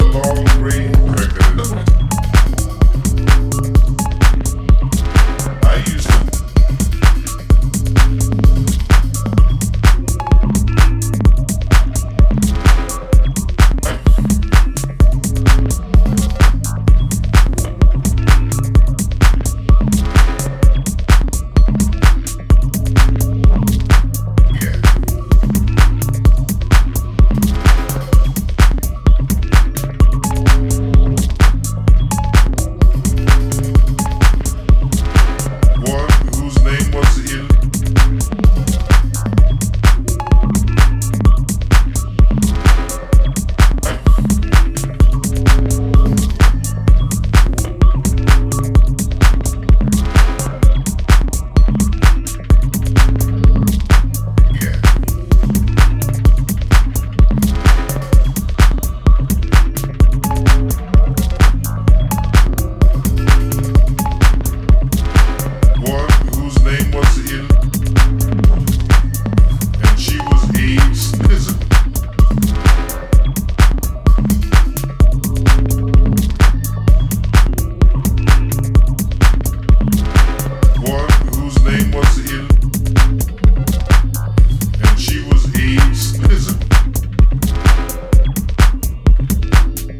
不定形に揺らぐダビーな空間にピッチを落としたヴォイスや妖しいシンセが散りばめられた